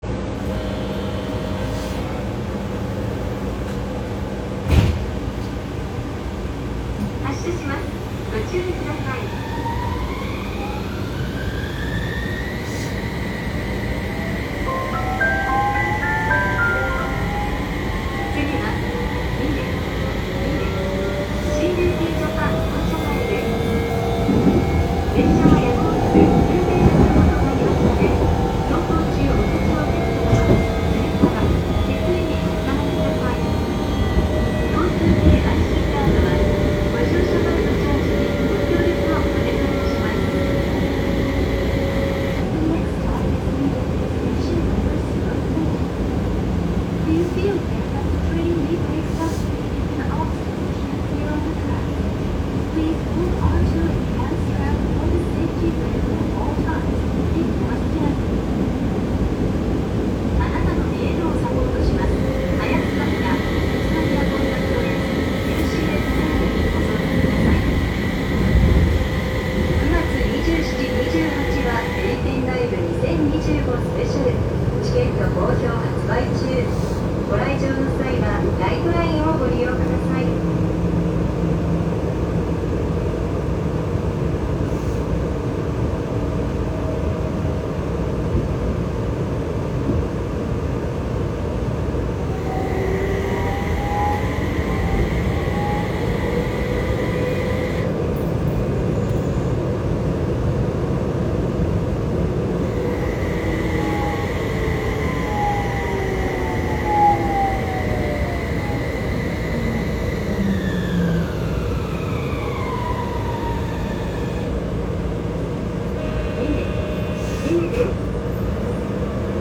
・HU300形走行音
路面電車車両にはありがちな三菱IGBTで特段目立った音ではありません。停車中に少々耳に残るブザーが流れ続けるほか、車内チャイムの種類がやたらと多く、個性はそこに見出せそうです。